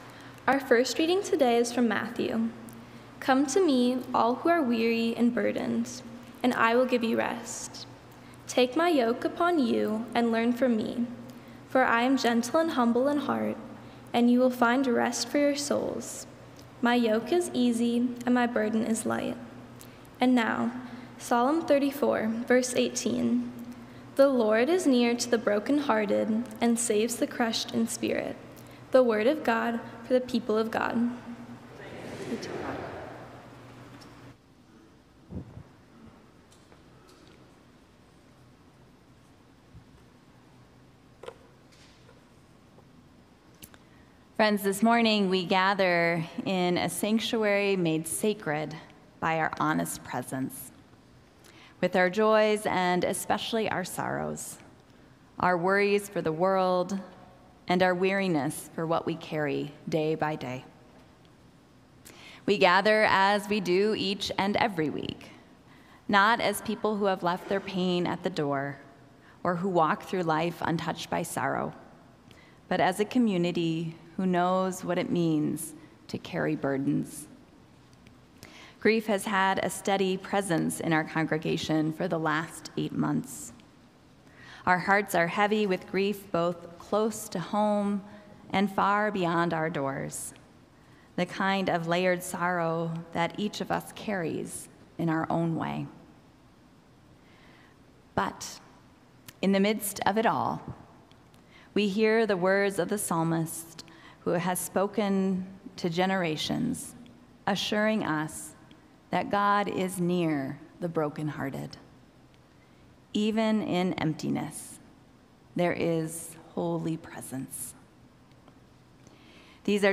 Join us this Sunday for “Lay Down Your Burdens,” a worship service where we gather in honesty and hope, naming our grief and lifting it to God.
Inspiring music and the blessing of life in community will lift us up.